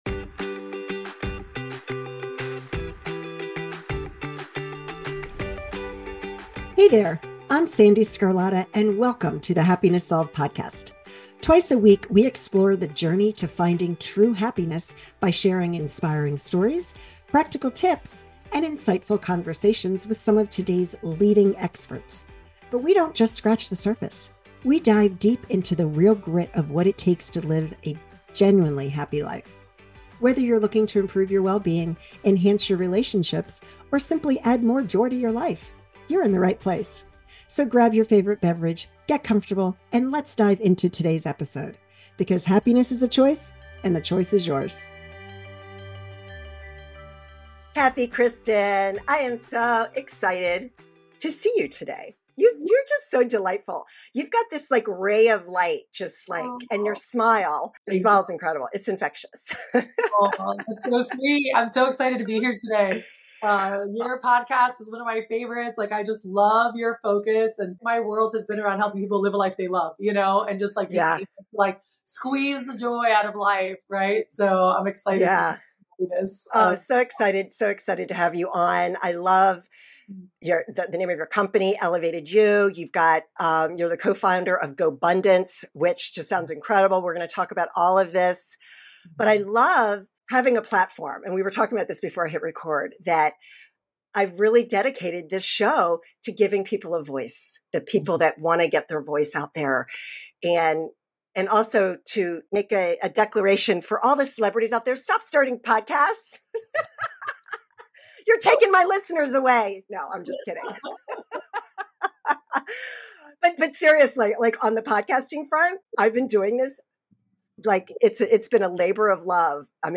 In this engaging conversation